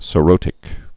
(sə-rōtĭk, -rŏtĭk)